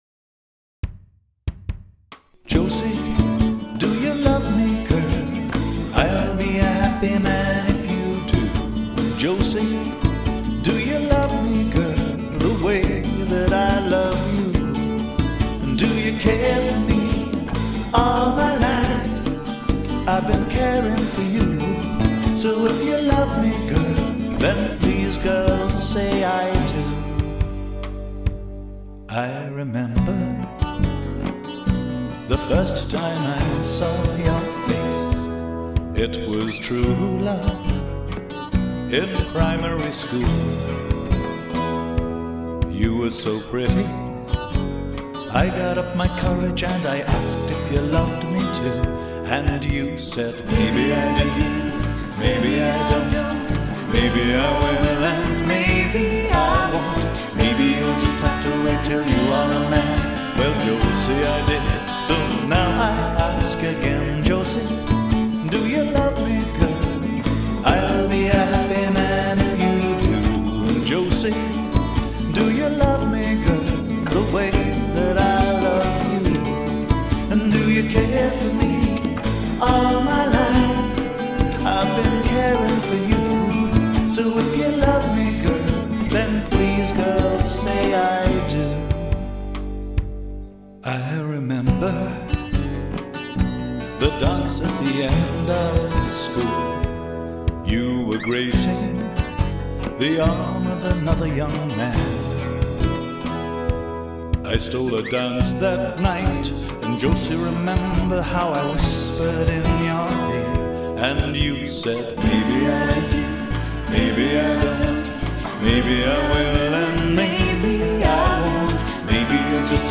Male
English (Australian)
Older Sound (50+)
I am also a singer/songwriter and can provide backing music.
Singing
Original Song.
0122Song_-_A_Proposal.mp3